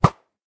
bow.mp3